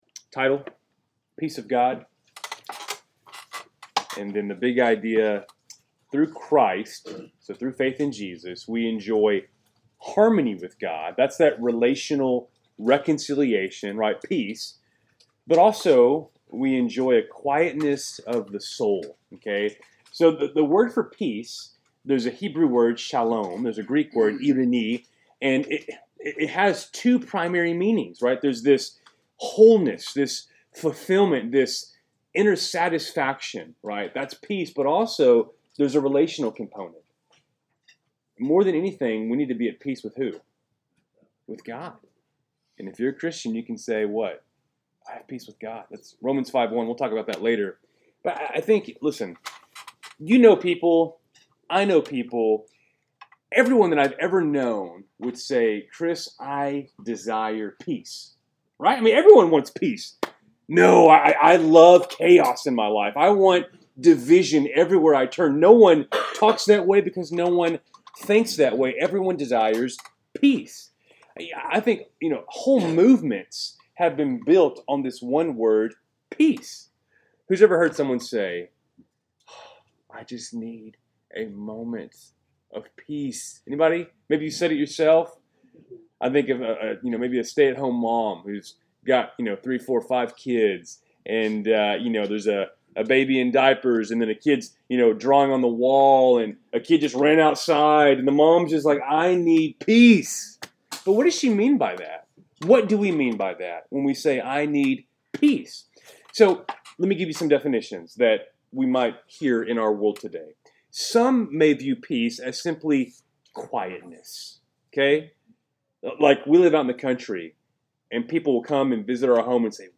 3-26-25-Wednesday-Night-Bible-Study.mp3